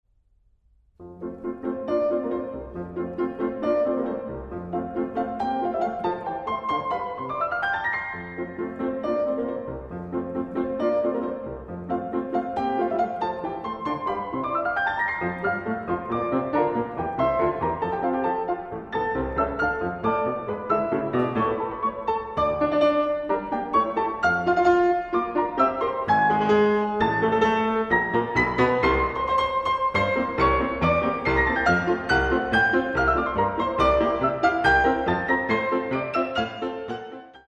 arr. for piano 4 hands by composer